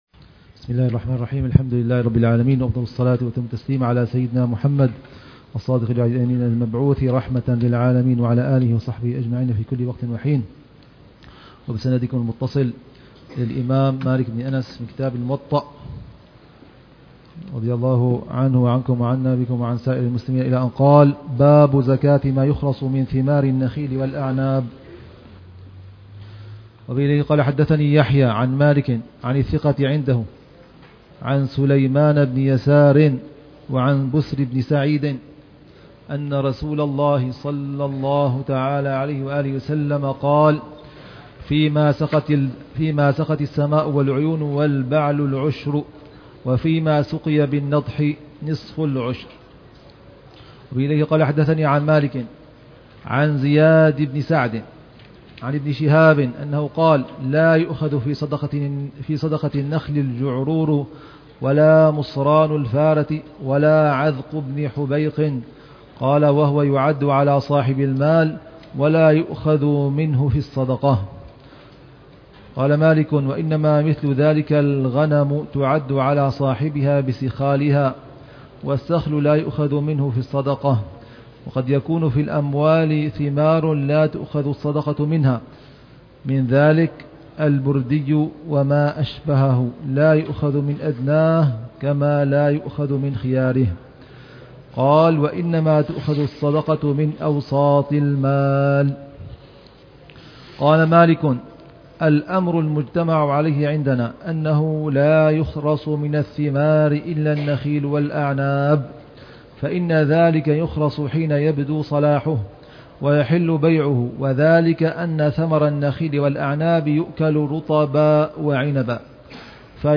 شرح فضيلة الحبيب العلامة عمر بن محمد بن حفيظ على كتاب الموطأ لإمام دار الهجرة الإمام مالك بن أنس الأصبحي، برواية الإمام يحيى بن يحيى الليثي، ك